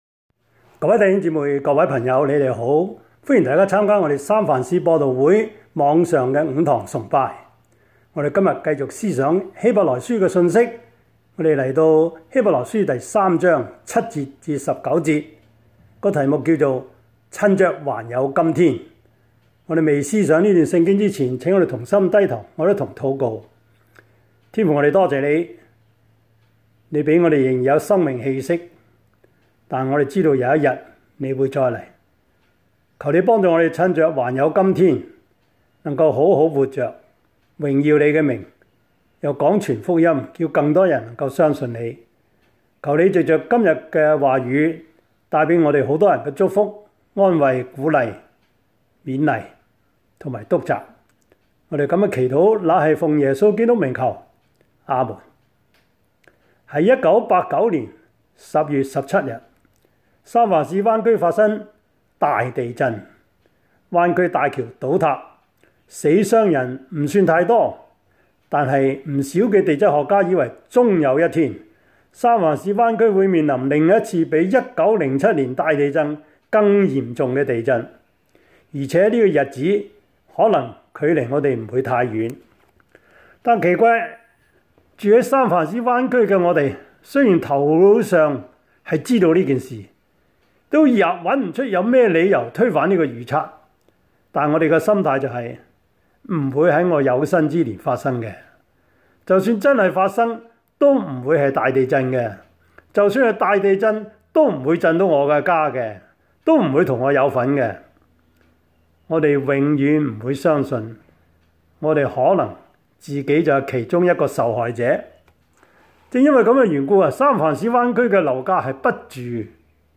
Service Type: 主日崇拜
Topics: 主日證道 « 只要一心為善, 結果交給上帝 耶穌醫罪人 »